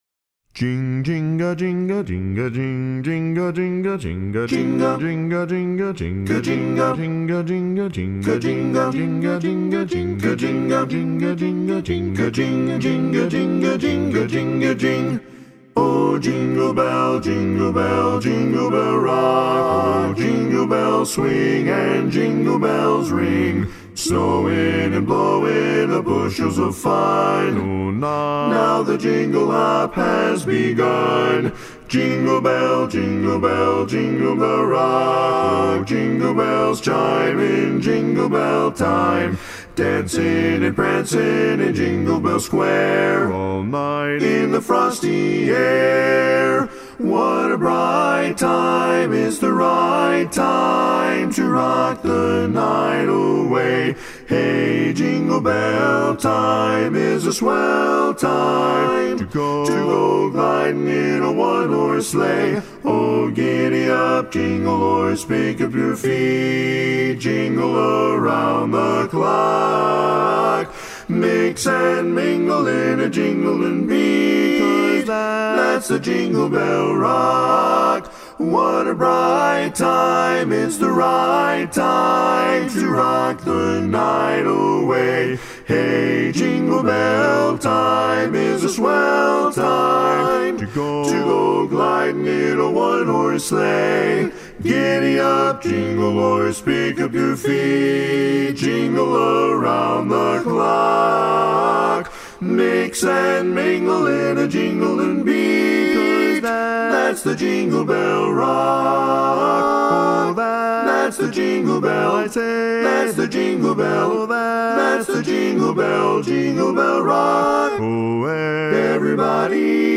Christmas Songs
Up-tempo
Barbershop
Tenor